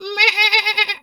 sheep_2_baa_high_01.wav